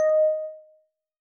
bell